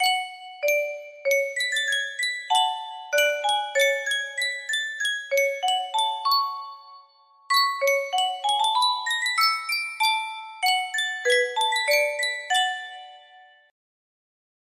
Yunsheng Caja de Musica - La Borinquena 6360 music box melody
Full range 60